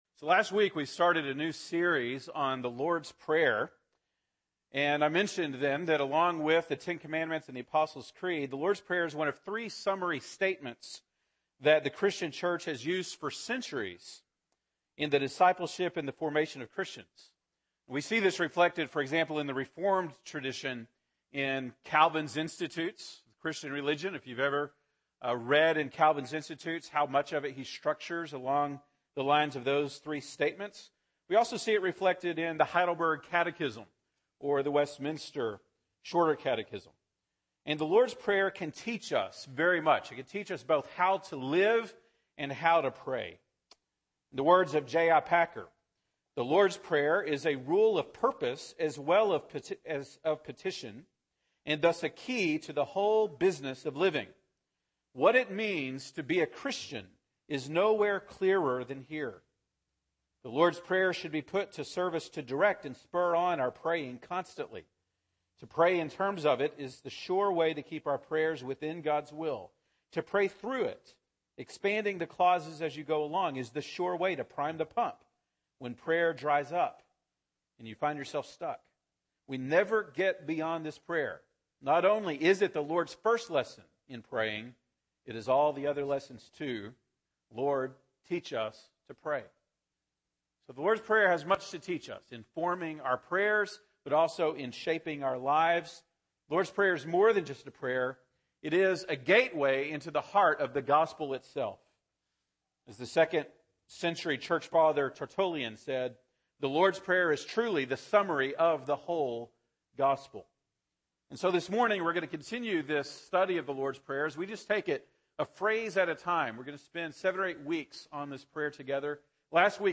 October 12, 2014 (Sunday Morning)